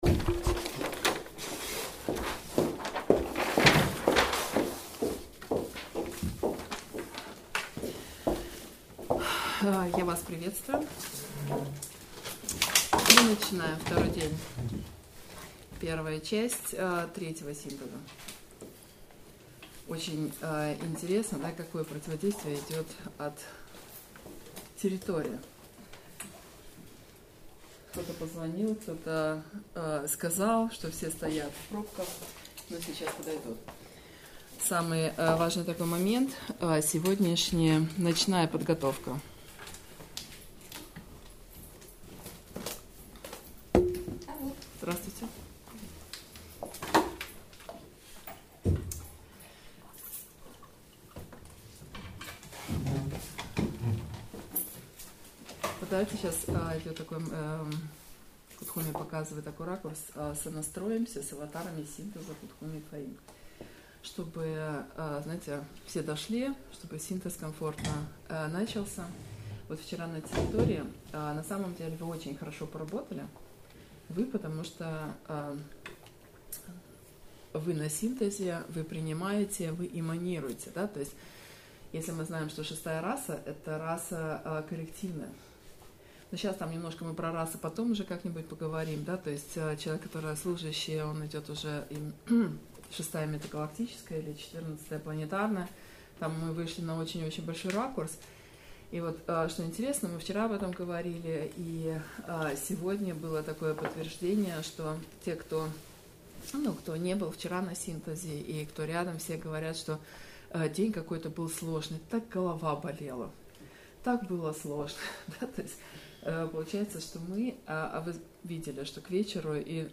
Слушать/смотреть Аудио/видео скачать Часть 1: Часть 2: Часть 3: Часть 4: Часть 1: Аудио Часть 2: Аудио Часть 3: Аудио Часть 4: Аудио 1 часть 2-го дня по техническим причинам только 7 минут записалась.